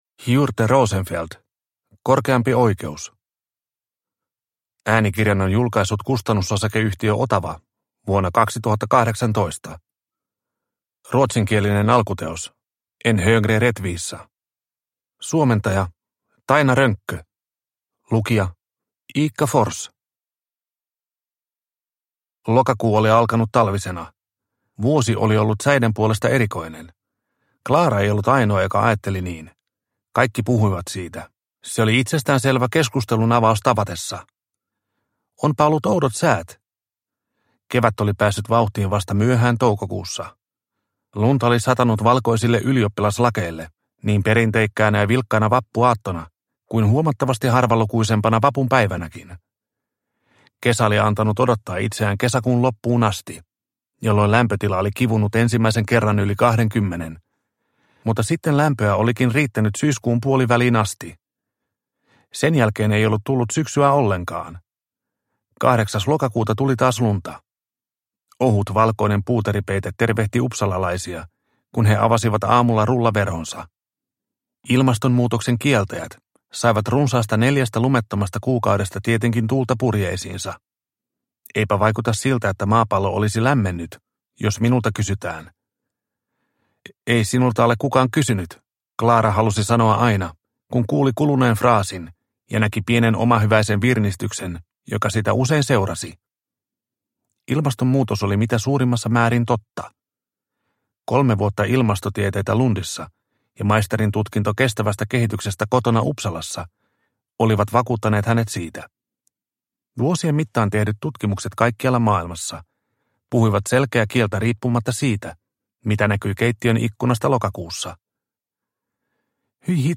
Korkeampi oikeus – Ljudbok – Laddas ner